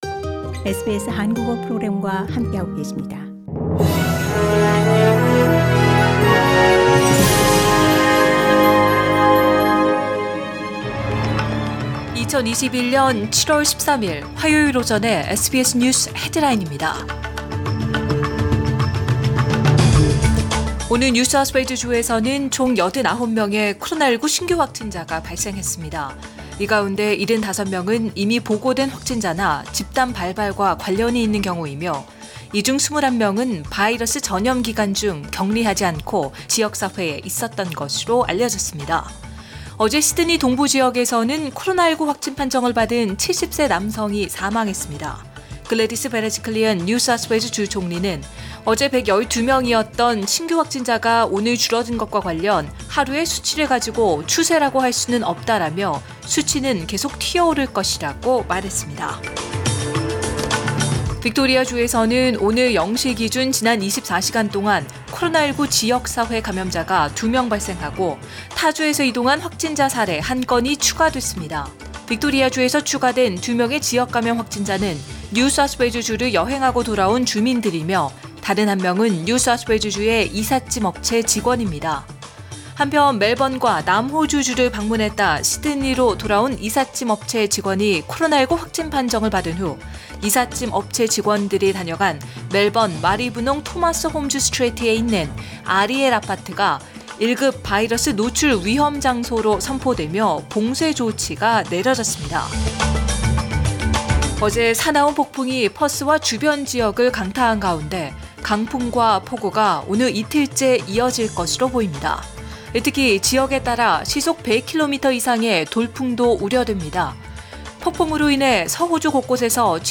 2021년 7월 13일 화요일 오전의 SBS 뉴스 헤드라인입니다.